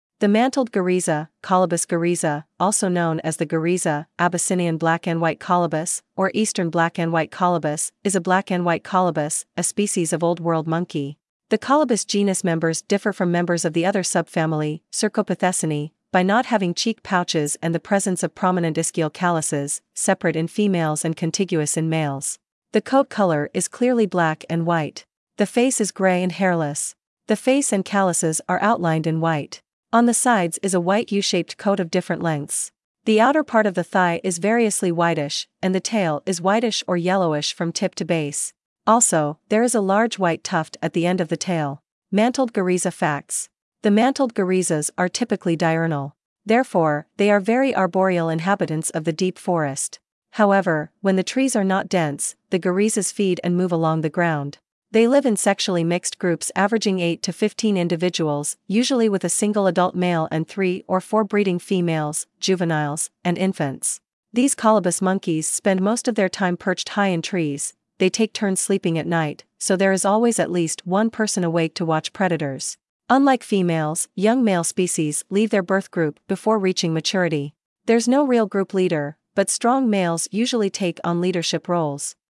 Mantled guereza
Mantled-guereza.mp3